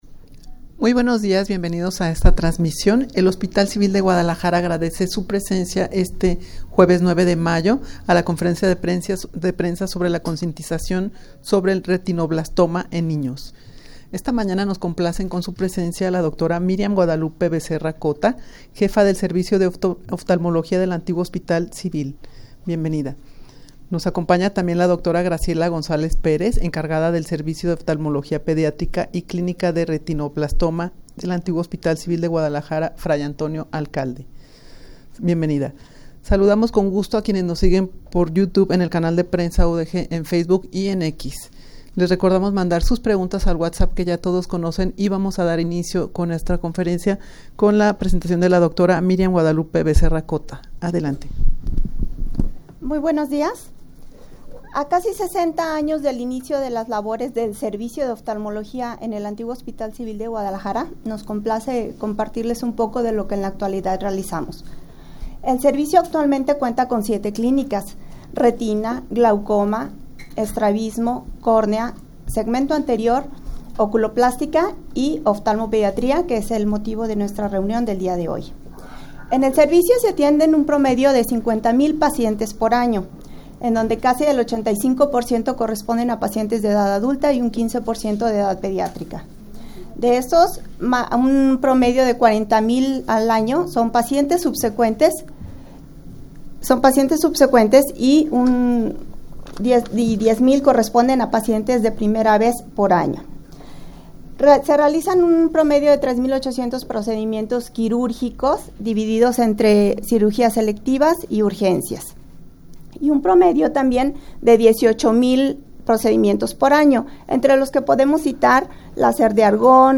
rueda-de-prensa-acerca-de-la-concientizacion-sobre-el-retinoblastoma-en-ninos-tumor-maligno-en-retina.mp3